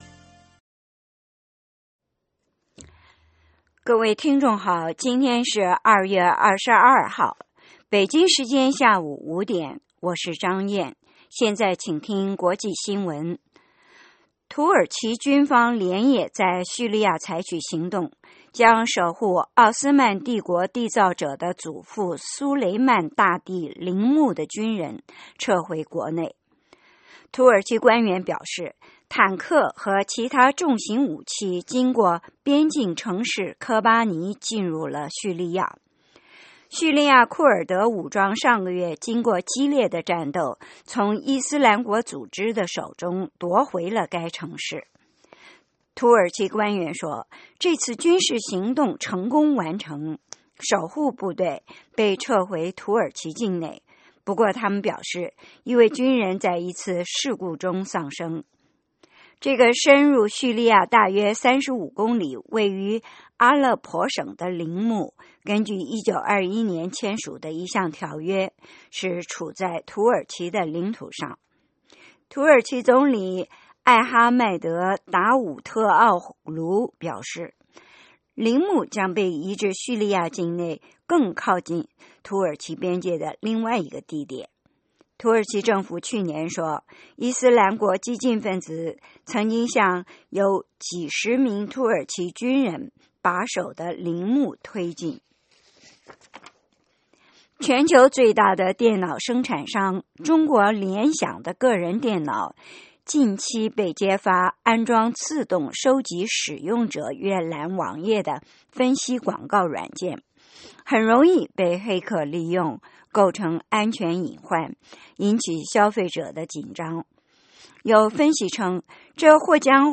北京时间下午5-6点广播节目。 内容包括国际新闻和美语训练班（学个词，美国习惯用语，美语怎么说，英语三级跳，礼节美语以及体育美语）